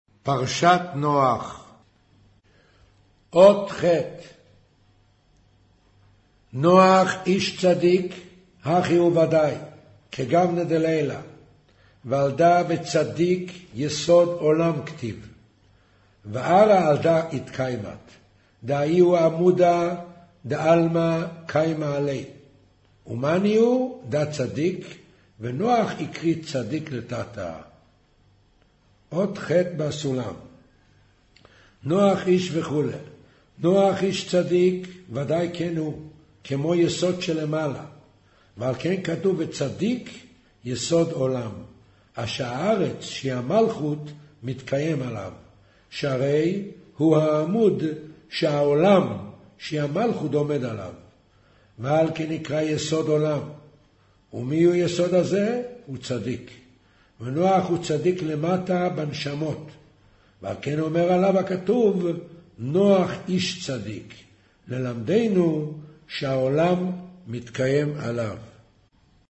קריינות זהר, פרשת נח, מאמר נח ותיבה אות ח'